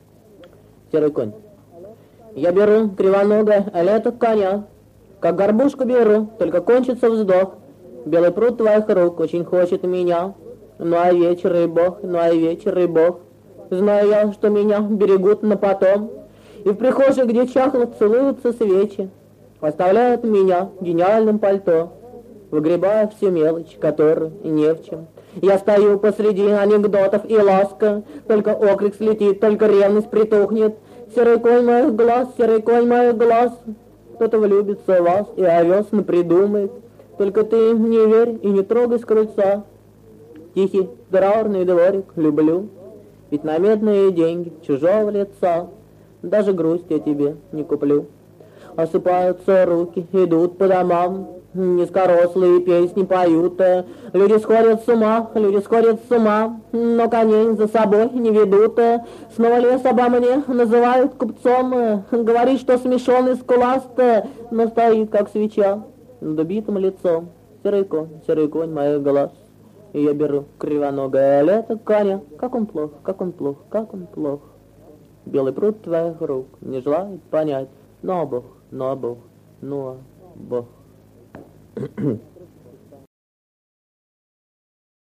Gubanov-Ya-beru-krivonogoe-leto-konya..-chitaet-avtor-stih-club-ru.mp3